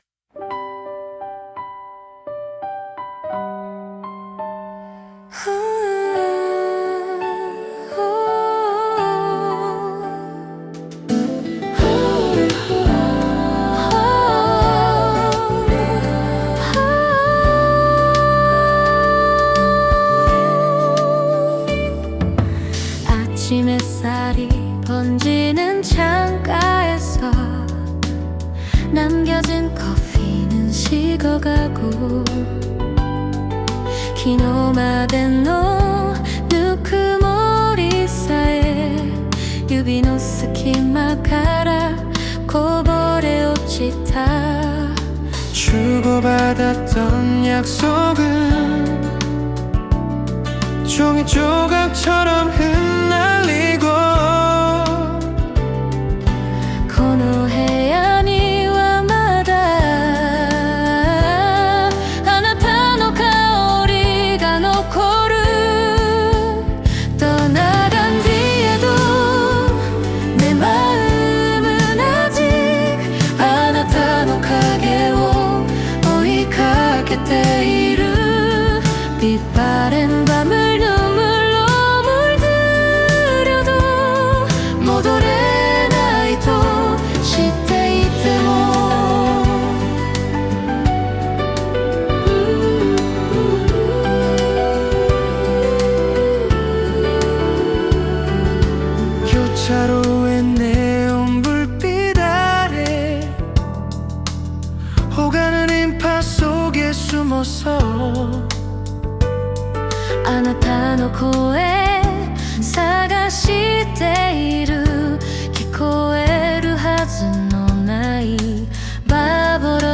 Korean–Japanese mixed duet City Pop ballad inspired by 015B\\\\\\'s \\\\\\\"떠나간 후에\\\\\\\", Vocal 1: Japanese female, soft & breathy in verses, fuller in choruses, natural pronunciation, Vocal 2: Korean female, warm clear tone, expressive vibrato, smooth